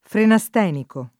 vai all'elenco alfabetico delle voci ingrandisci il carattere 100% rimpicciolisci il carattere stampa invia tramite posta elettronica codividi su Facebook frenastenico [ frena S t $ niko ] agg. e s. m. (med.); pl. m. -ci